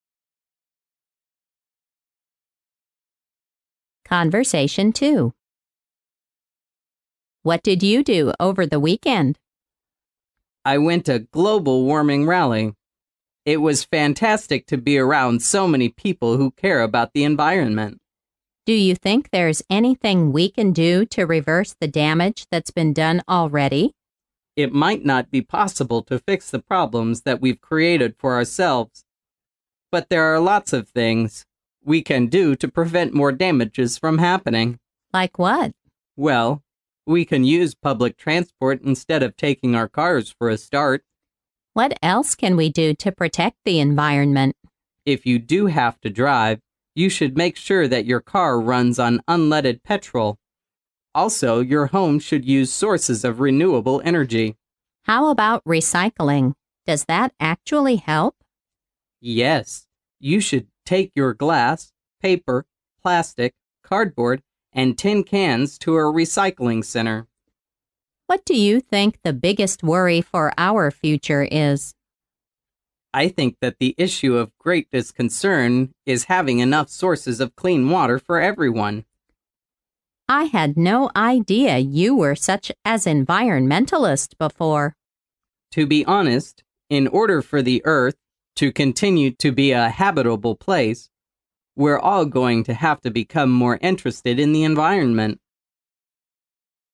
潮流英语情景对话张口就来Unit17：全球变暖mp3